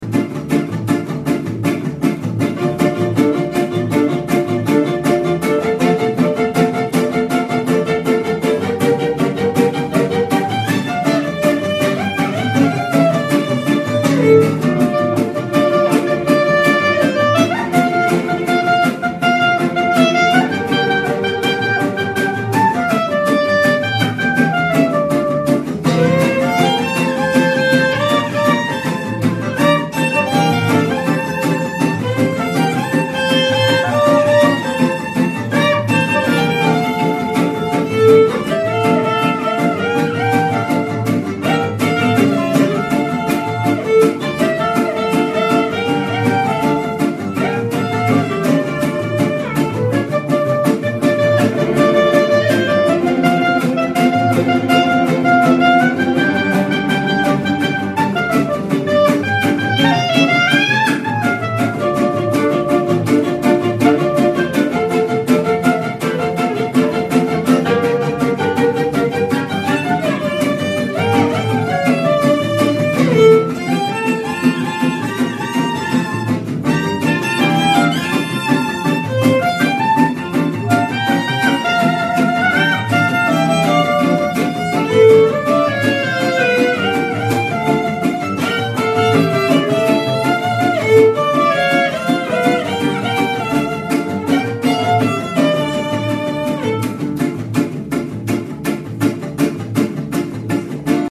Mariage juif : un groupe de musique pour une prestation Klezmer
Avec l’orchestre Swing Klezmer Orchestra, personnalisez la musique juive de votre mariage
Nos musiciens vous proposent un vaste répertoire de musiques klezmer, israéliennes, yiddish.